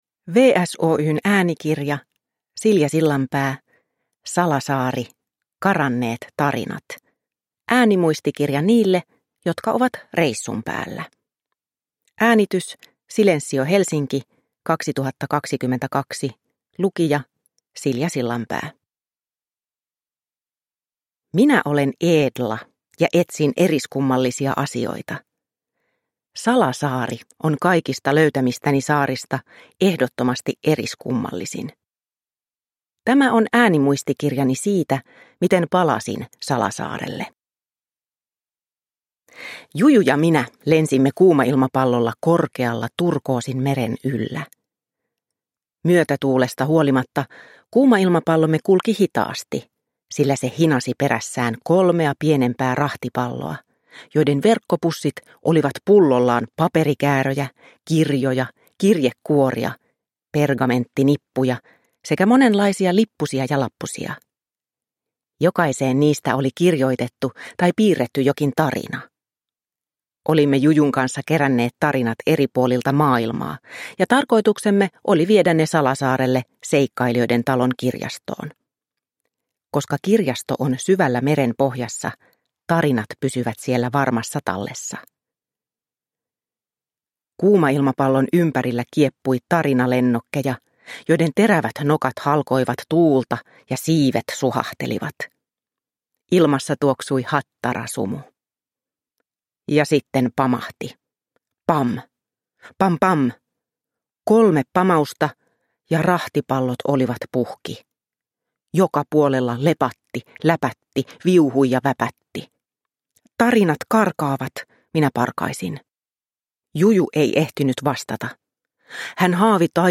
Salasaari: Karanneet tarinat – Ljudbok – Laddas ner